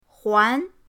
huan2.mp3